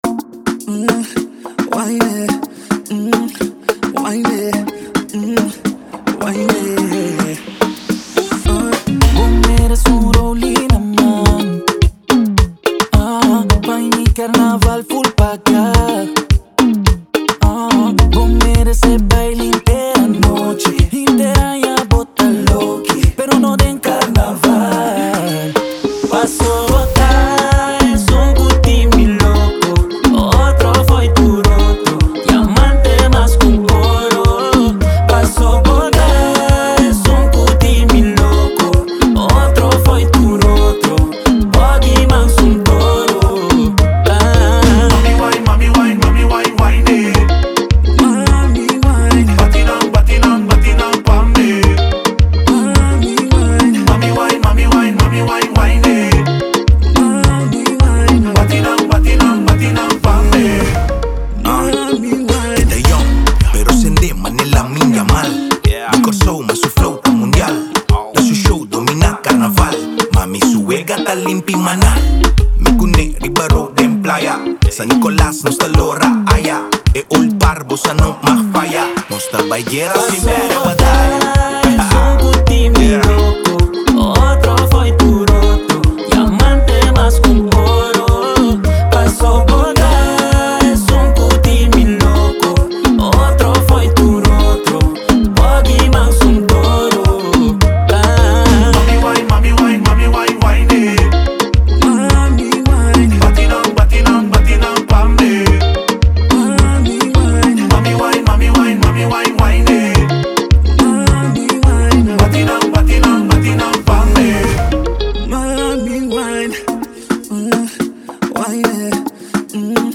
Zouk/Soca • Clean version